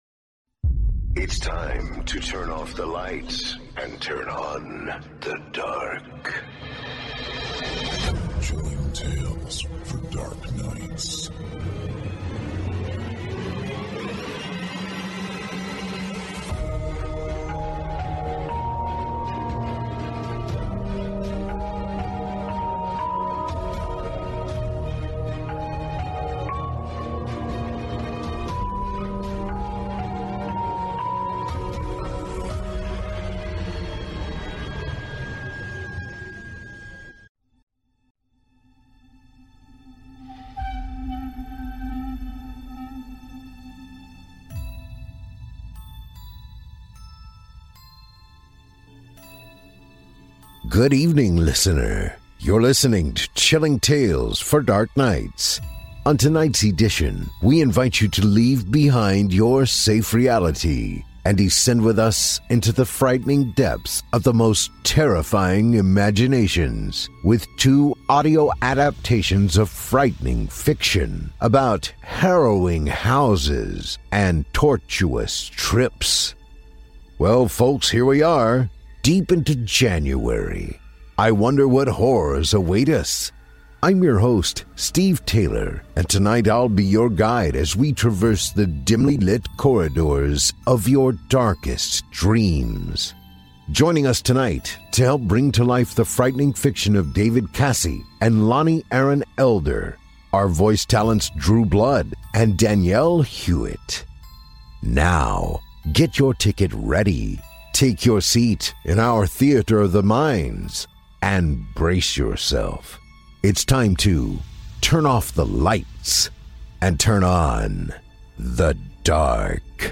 On tonight’s edition, we invite you to leave behind your safe reality, and descend with us into the frightening depths of the most terrifying imaginations, with two audio adaptations of frightening fiction, about harrowing houses and torturous trips.